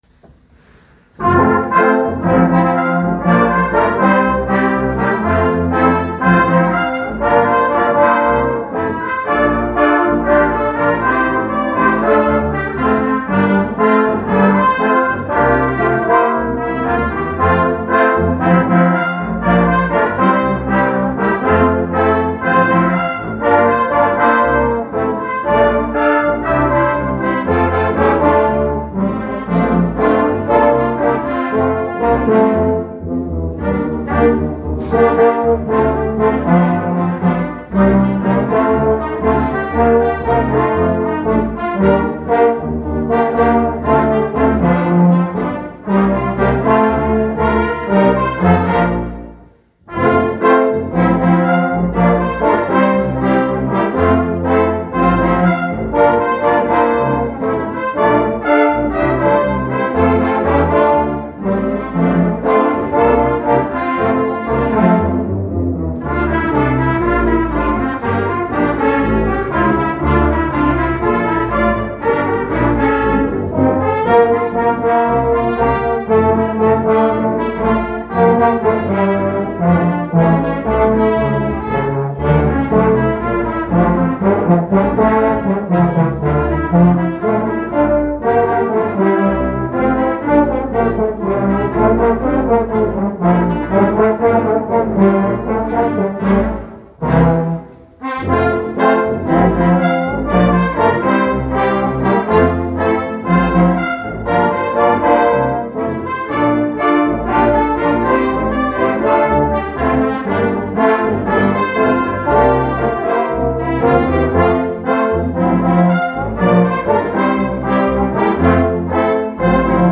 Oktober 2025 – Posaunenchor Vincenzenbronn
Europäisches Partnerschaftsfest
Verschiedenste Gruppierungen traten am Sonntag beim Europäischen Markt im Festzelt auf der Kuhrswiese auf. Wir waren ebenso mit eingeladen und steuerten Musik aus unserem aktuellen Programm bei.